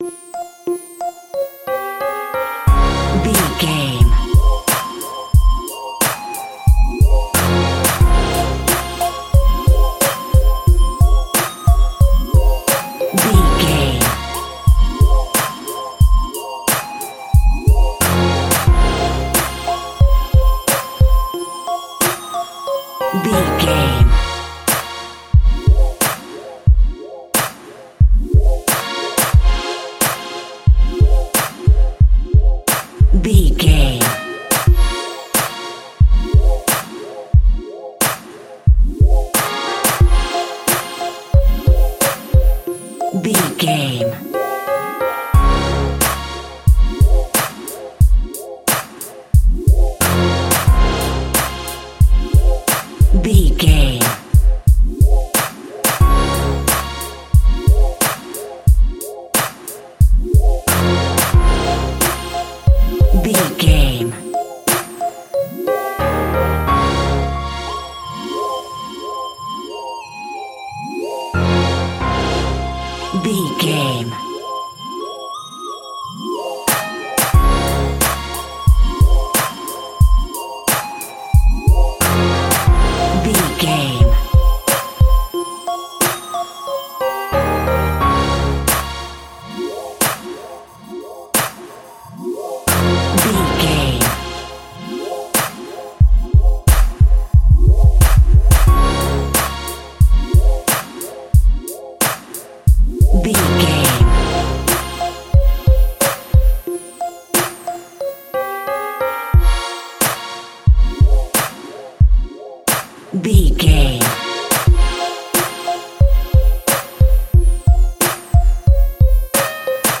Aeolian/Minor
synthesiser
drum machine
hip hop
soul
Funk
acid jazz
energetic
cheerful
bouncy
Triumphant
funky